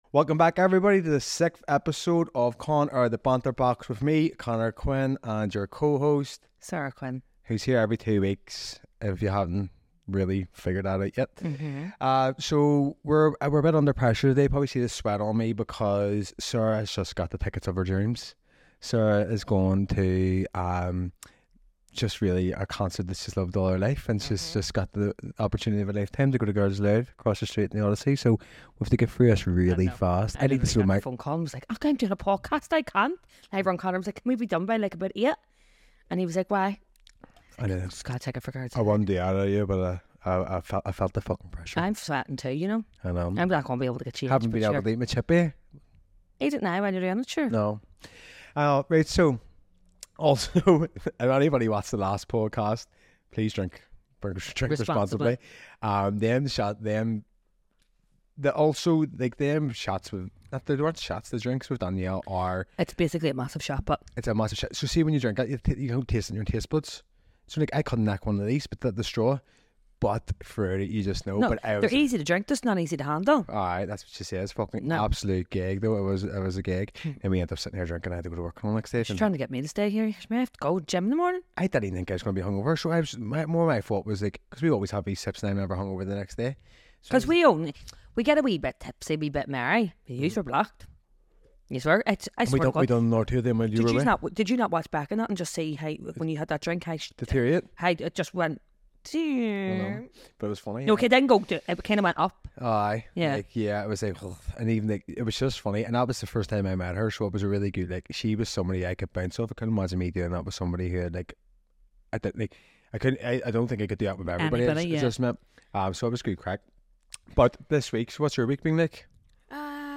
Enjoy singing along with us throughout the episode, and don’t forget to share your thoughts and questions.